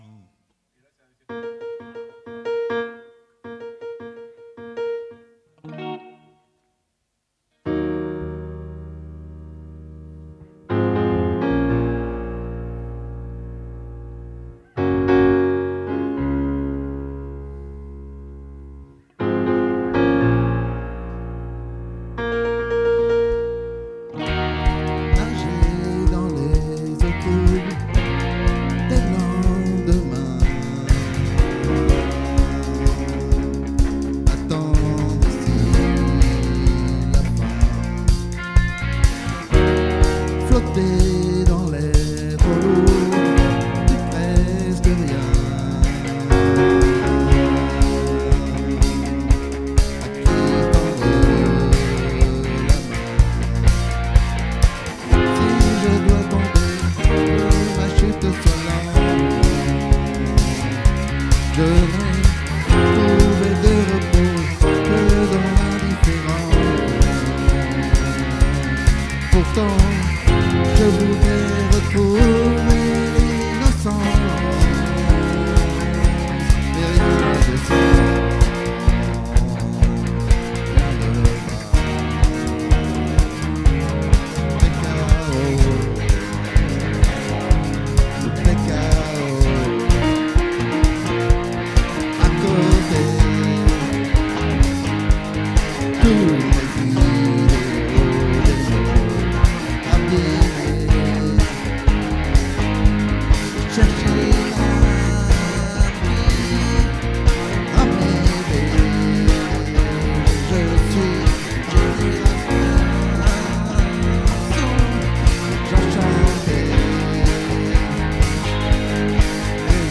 🏠 Accueil Repetitions Records_2025_10_06